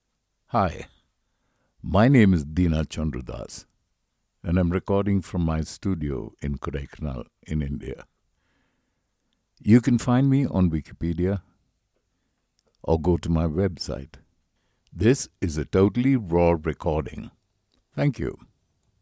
Male
60s
Authoritative, Confident, Conversational, Deep
Commercial, Corporate, Documentary, E-Learning, Explainer, Narration
Microphone: Samson C01U pro